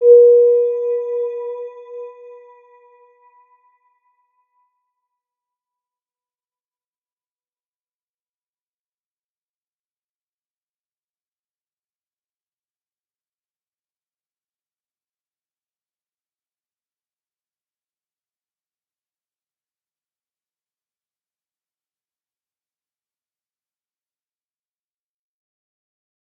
Round-Bell-B4-p.wav